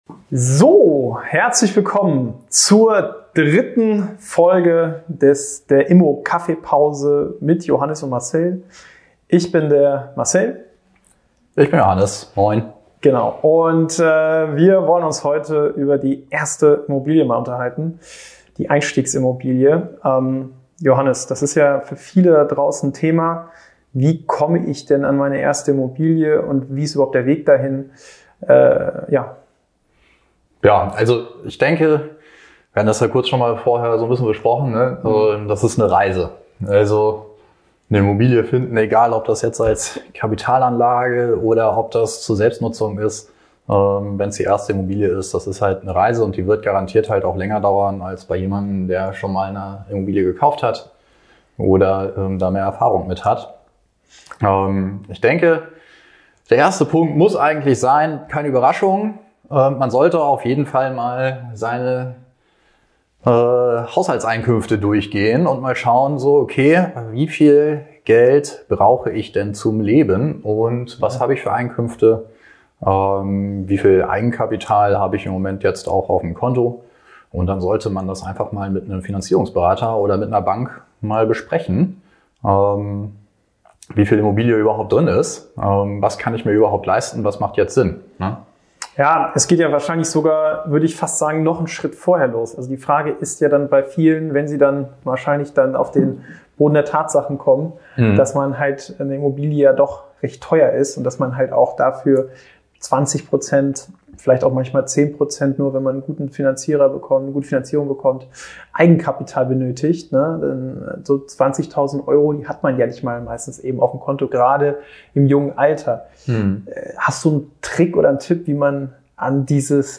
Wir haben uns gedacht, wir berichten einfach mal ungeskriptet und unverfälscht direkt aus unserem Immobilienmakler-Alltag und quatschen einfach über unterschiedliche Themen aus der Immobilien-Branche.